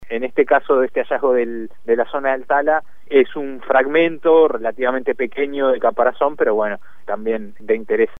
En conversación con El Espectador